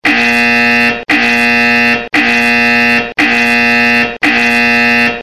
Genre: Nada dering lucu Tag